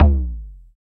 LOGTOM LO1M.wav